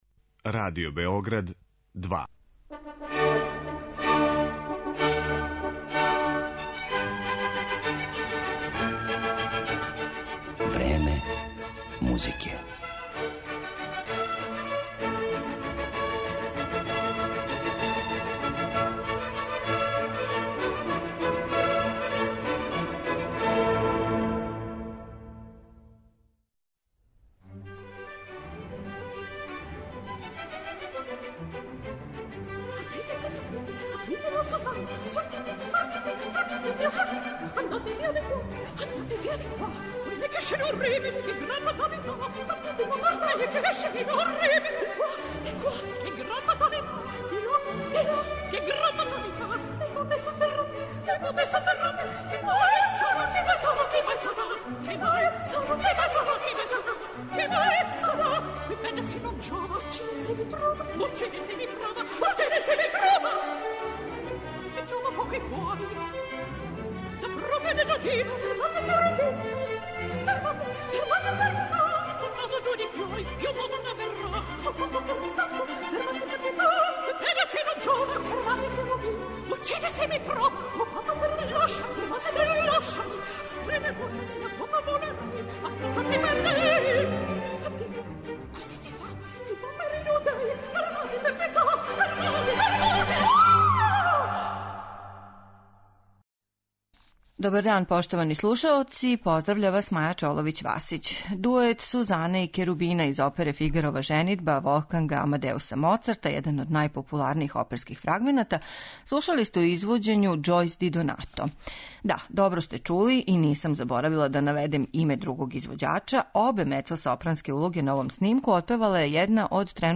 мецосопран